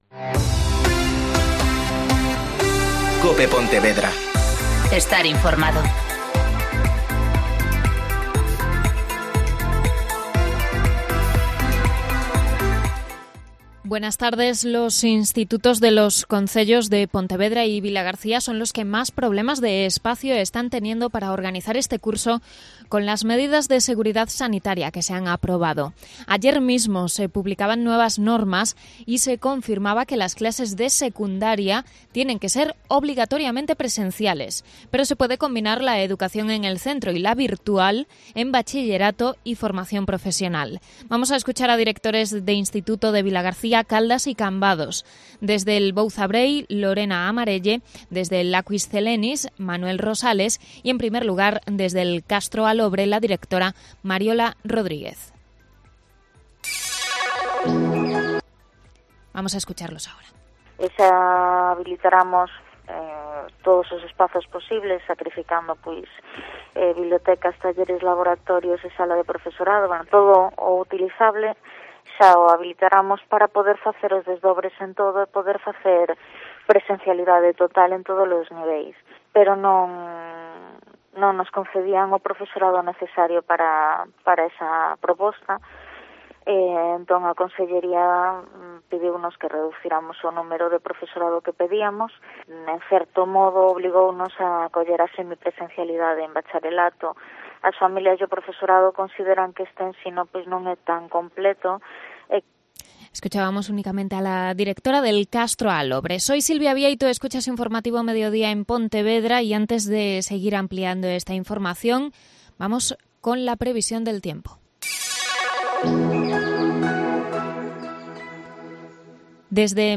Mediodia COPE Pontevedra (Informativo 14:20h)